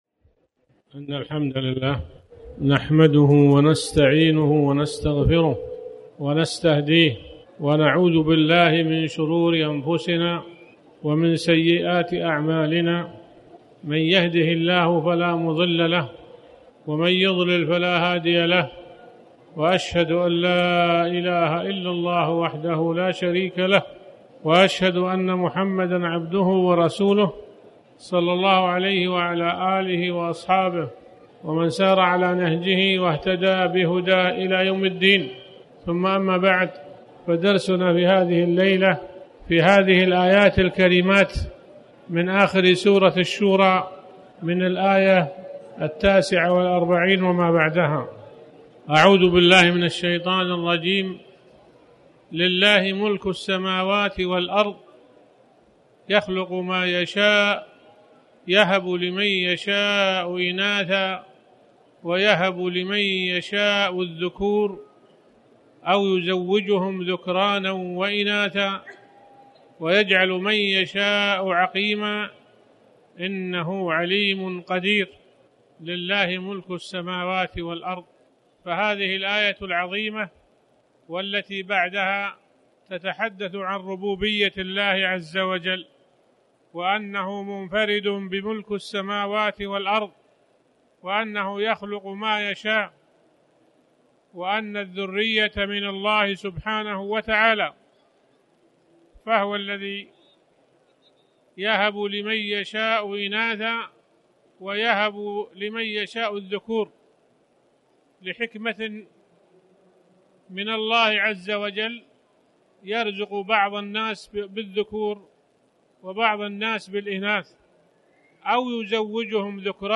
تاريخ النشر ٢٤ صفر ١٤٣٩ هـ المكان: المسجد الحرام الشيخ